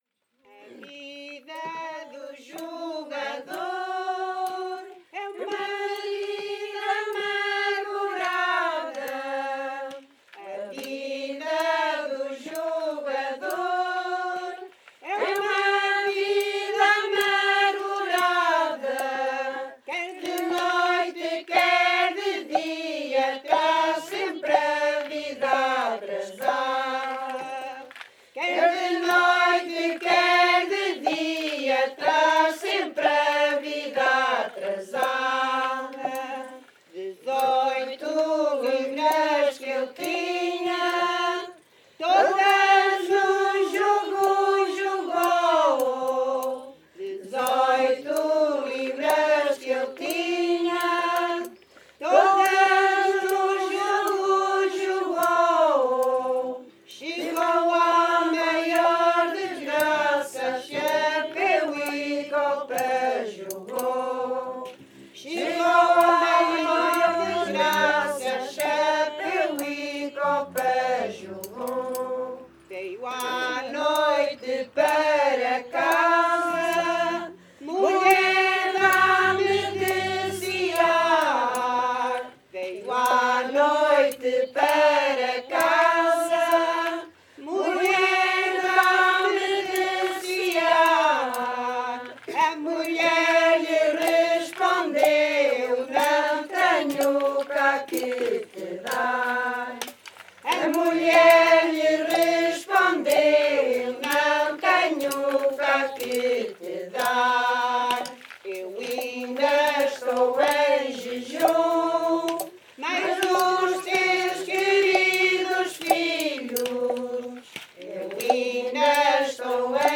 Grupo Etnográfico de Trajes e Cantares do Linho
A vida do jogador (Versão 1) (Várzea de Calde, Viseu)